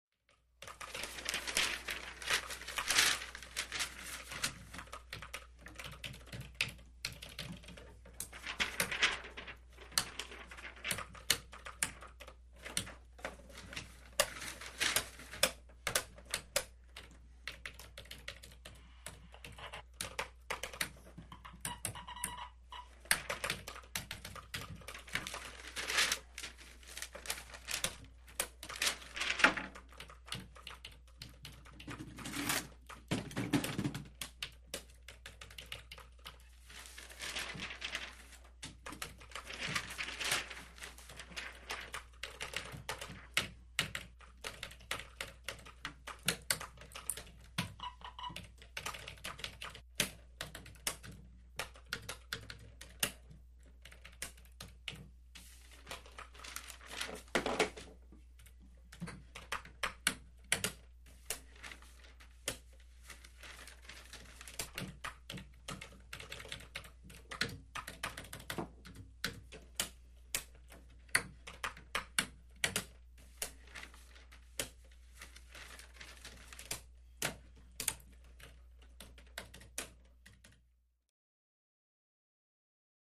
Office Background: Typing And Paper Movement.